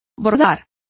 Complete with pronunciation of the translation of embroidering.